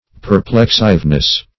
Search Result for " perplexiveness" : The Collaborative International Dictionary of English v.0.48: Perplexiveness \Per*plex"ive*ness\, n. The quality of being perplexing; tendency to perplex.
perplexiveness.mp3